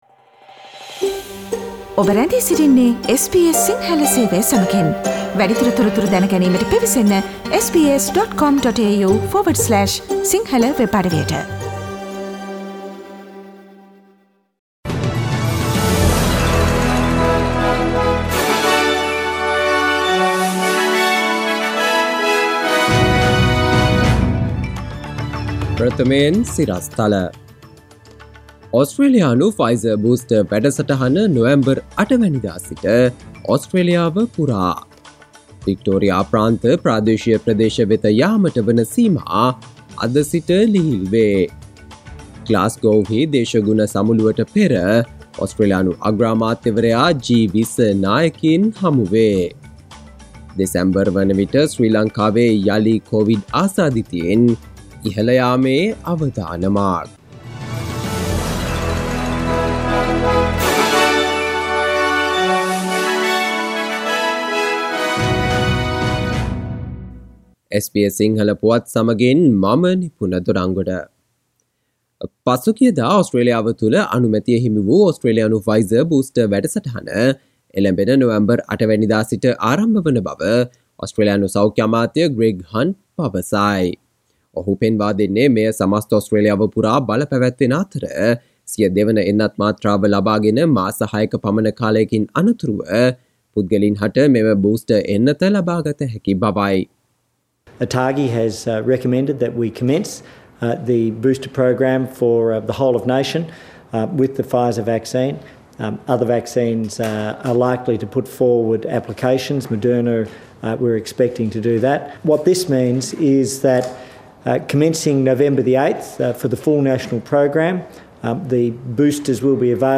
Listen to the latest news from Australia, Sri Lanka, across the globe, and the latest news from the sports world on SBS Sinhala radio news bulletin – Friday 29 October 2021